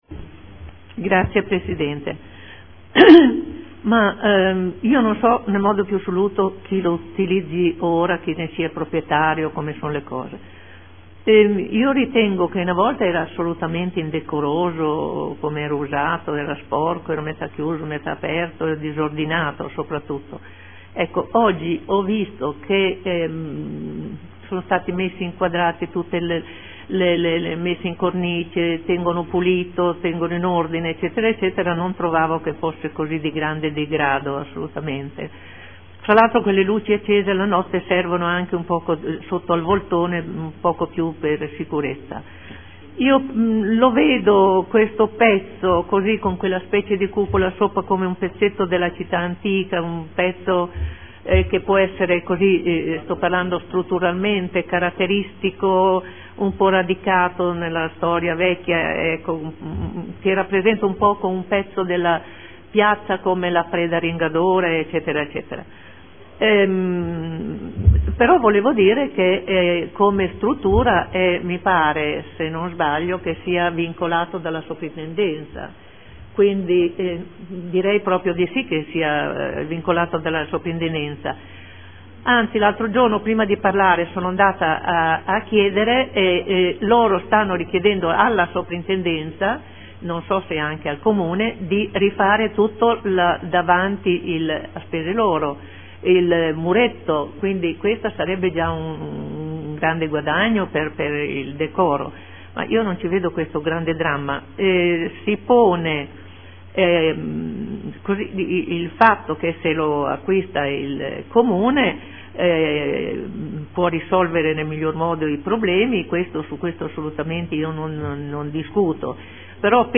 Olga Vecchi — Sito Audio Consiglio Comunale